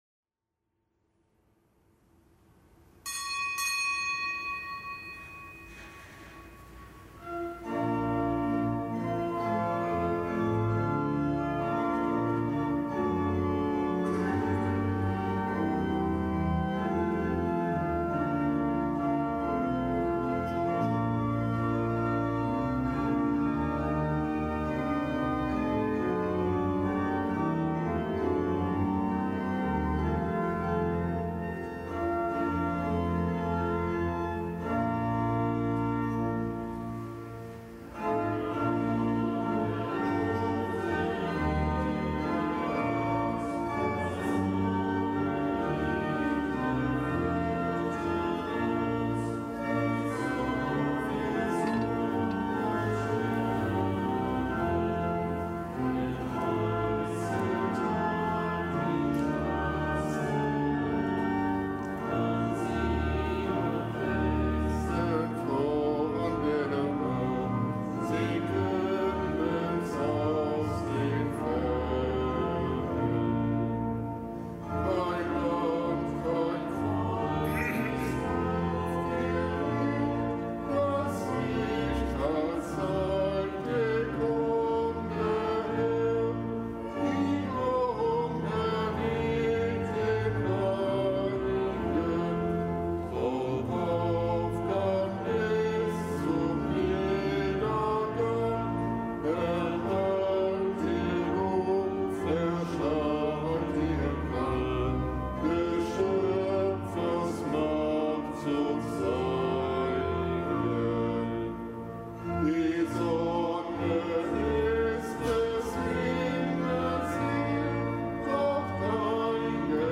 Kapitelsmesse aus dem Kölner Dom am Mittwoch der dritten Fastenwoche. Nichtgebotener Gedenktag Heiliger Liudger, Bischof von Münster, Glaubensbote.